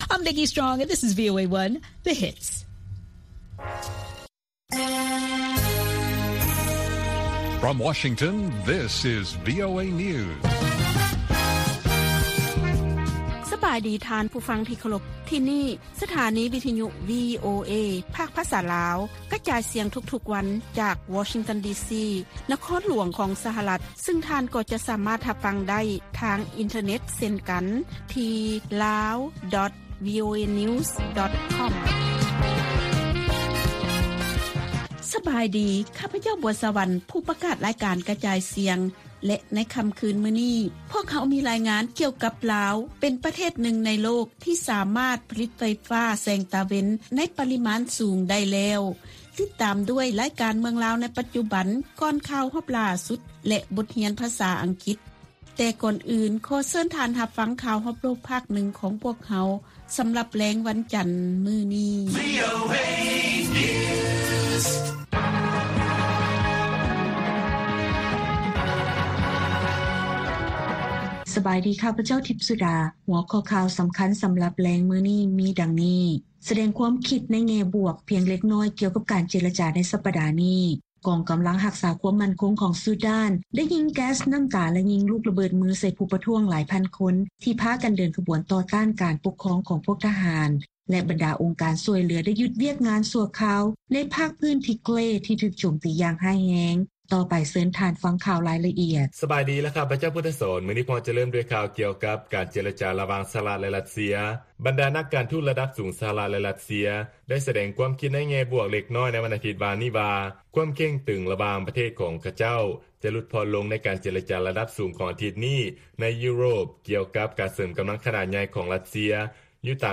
ວີໂອເອພາກພາສາລາວ ກະຈາຍສຽງທຸກໆວັນ, ຫົວຂໍ້ຂ່າວສໍາຄັນໃນມື້ນີ້ມີ: 1. ສະຫະລັດ ແລະ ຣັດເຊຍ ສະແດງຄວາມຄິດໃນແງ່ບວກພຽງເລັກນ້ອຍ ກ່ຽວກັບການເຈລະຈາໃນສັບປະດານີ້, 2. ກອງກໍາລັງຮັກສາຄວາມໝັ້ນຄົງຂອງຊູດານ ໄດ້ຍິງແກັສ ນໍ້າຕາ ແລະຍິງລູກລະເບີດມື ໃສ່ຜູ້ປະທ້ວງຫຼາຍພັນຄົນ ທີ່ພາກັນເດີນຂະບວນຕໍ່ຕ້ານການປົກຄອງປະເທດ ຂອງພວກທະຫານ, ແລະ 3. ບັນດາອົງການຊ່ວຍເຫຼືອ ໄດ້ຢຸດວຽກງານຊົ່ວຄາວໃນພາກພື້ນ ທິເກຣ, ທີ່ຖືກໂຈມຕີຢ່າງຮ້າຍແຮງ.